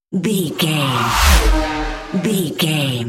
Whoosh electronic metal
Sound Effects
Atonal
futuristic
high tech
intense